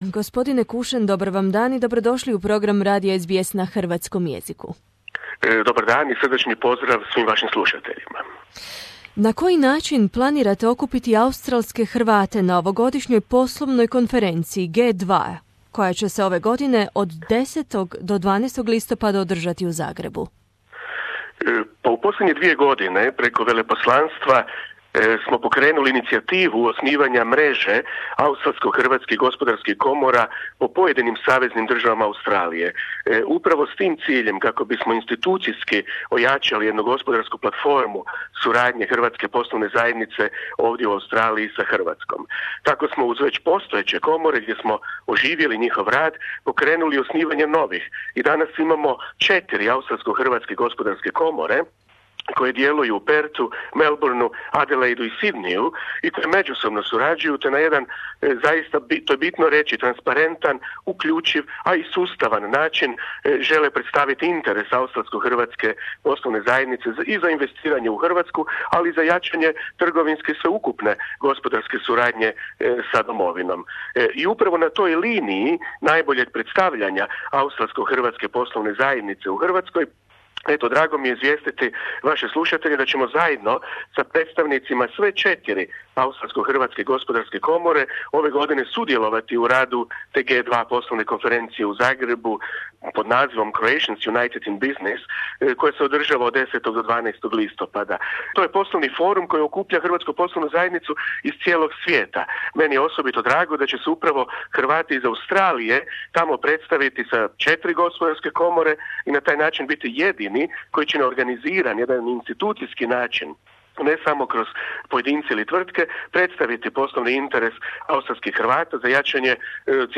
U Hrvatskoj se, pod pokroviteljstvom RH i hrvatskog Ministarstva vanjskih poslova i ove godine održava susret druge generacije hrvatskih iseljenika G2.2. Ovu poslovnu konferenciju organizira skupina Hrvata iz dijaspore, a cilj je umrežavanje poslovnih ljudi iz Hrvatske i iseljeništva te jačanje ulaganja dijaspore u Hrvatsku. Veleposlanik RH u Canberri dr. Damir Kušen za naš program govori na koji način će australski Hrvati sudjelovati na ovogodišnjoj, drugoj po redu, poslovnoj konferenciji G2.2.